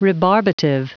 Prononciation du mot rebarbative en anglais (fichier audio)
Prononciation du mot : rebarbative